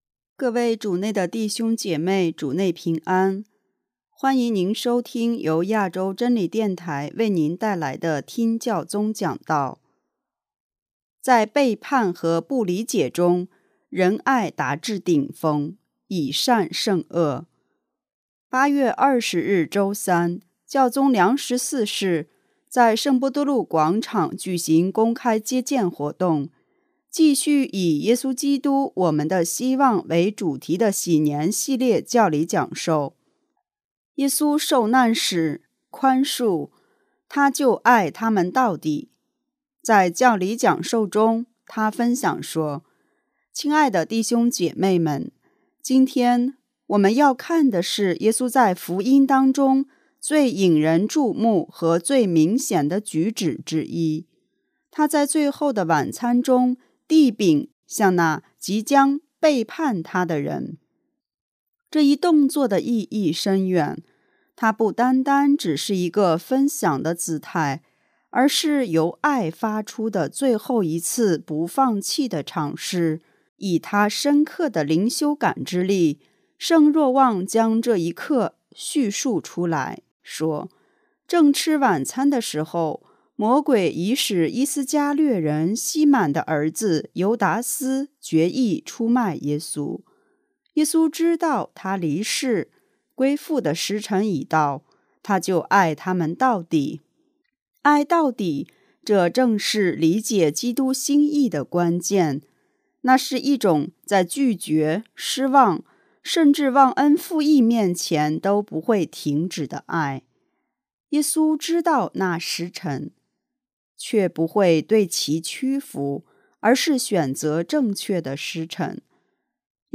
820日周三，教宗良十四世在圣伯多禄广场举行公开接见活动，继续以耶稣基督、我们的希望为主题的禧年系列教理讲授。“